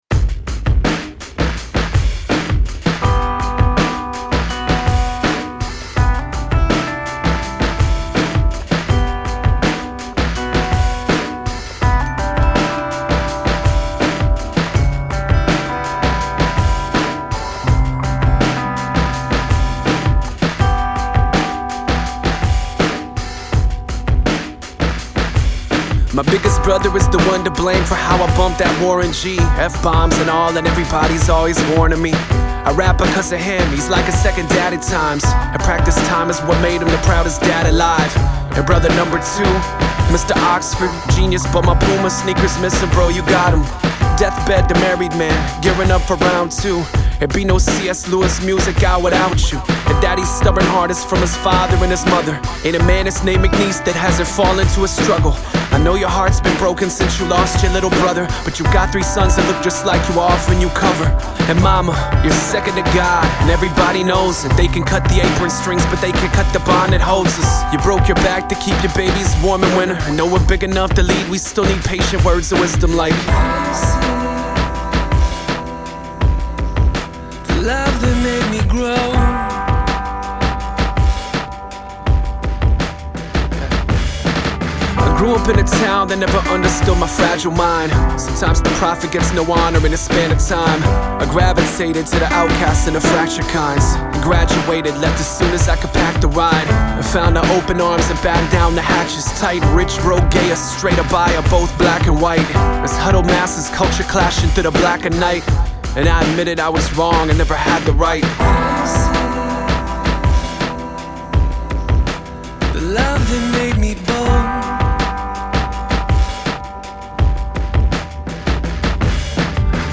O estilo musical escolhido foi o hip-hop.